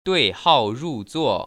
[duì hào rù zuò] 뚜이하오루쭈오